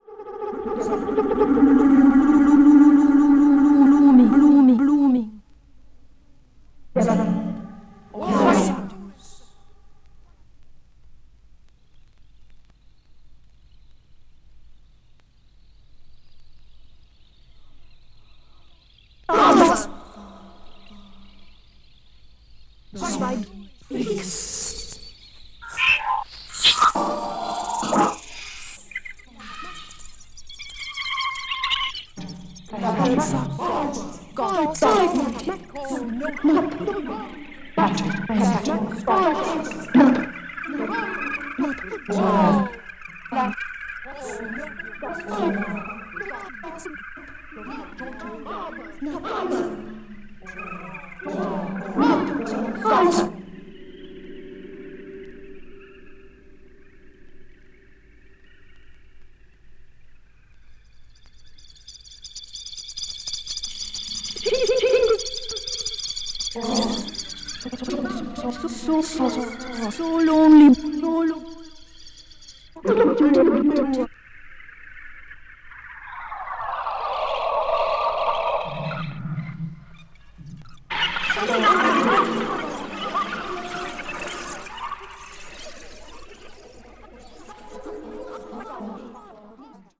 media : EX+/EX+(わずかにチリノイズが入る箇所あり)
ポエトリー・リーディングを解体再構築した電子音響